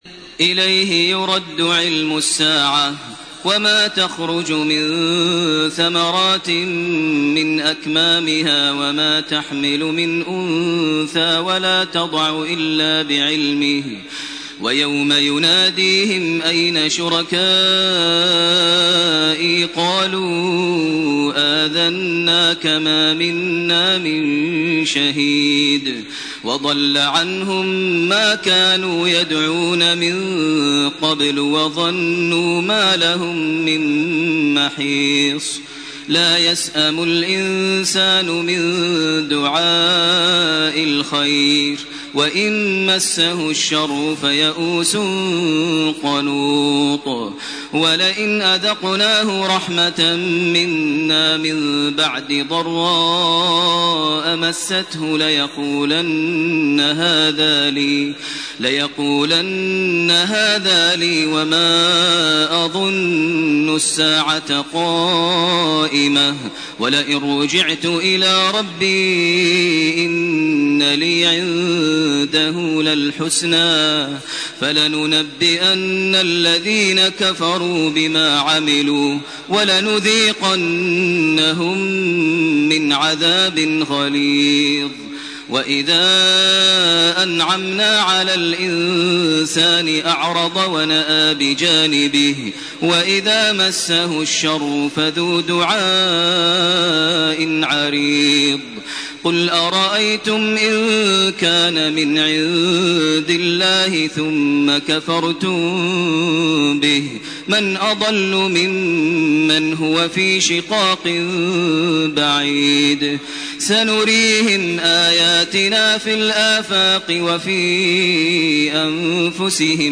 فصلت 31 - الي اخرها سورة الشوري سورة الزخرف من 1-24 > تراويح ١٤٢٨ > التراويح - تلاوات ماهر المعيقلي